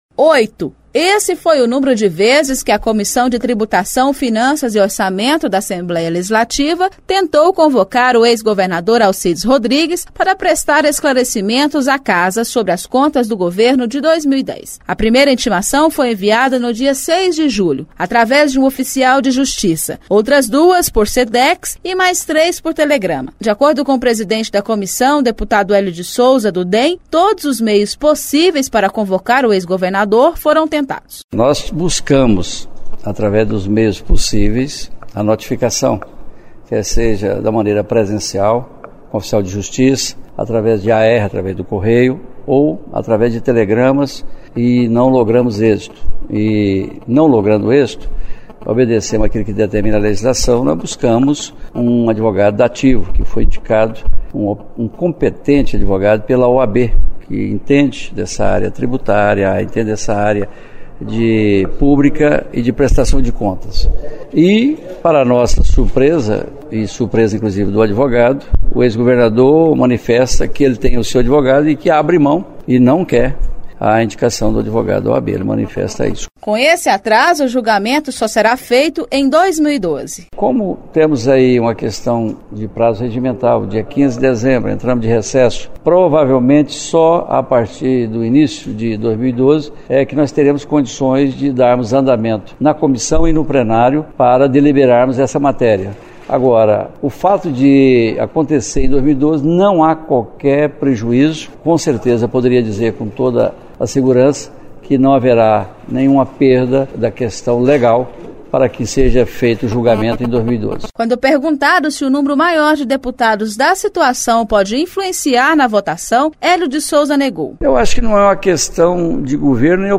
Presidente da Comissão de Tributação, Finanças e Orçamento, o deputado Helio de Sousa (DEM) afirmou, na tarde desta ferça-feira, 22, durante entrevista coletiva, concedida no Auditório Solon Amaral, que a Assembleia cumpriu todos os procedimentos jurídicos para notificar o ex-governador Alcides Rodrigues, para que apresentasse sua defesa no processo de julgamento das contas do seu governo relativas ao ano de 2010.